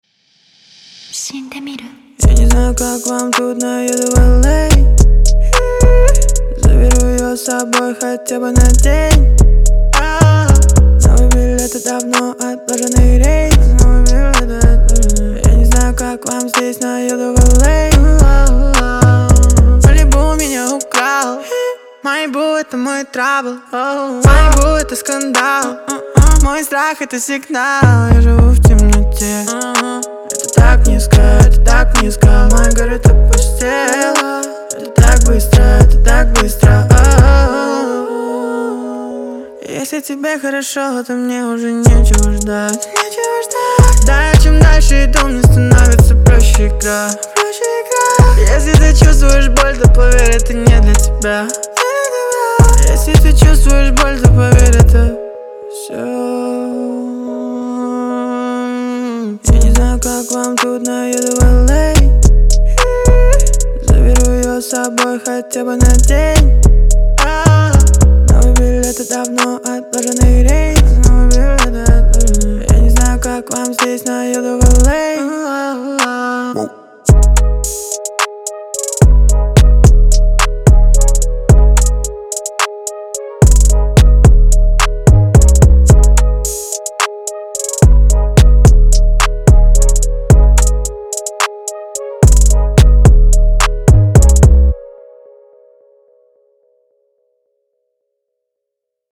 это яркая и энергичная песня в жанре поп